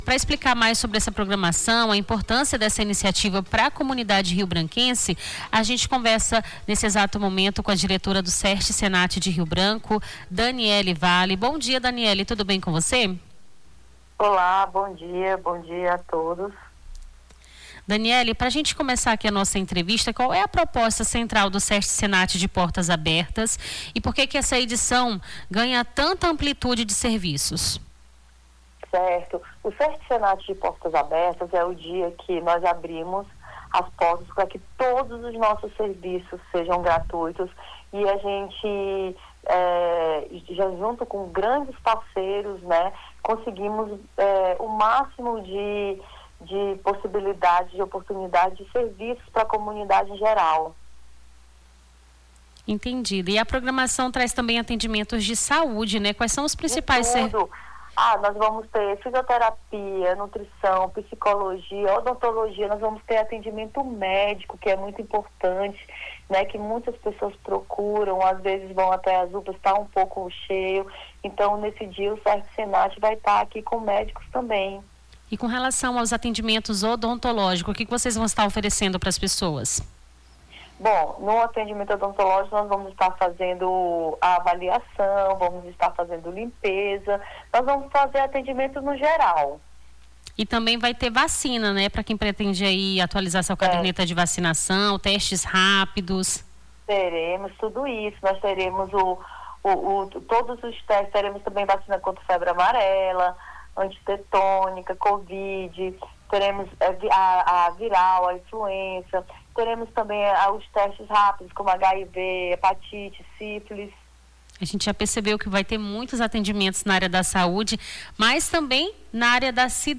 Nome do Artista - CENSURA - ENTREVISTA (ACAO SEST SENAT) 02-12-25.mp3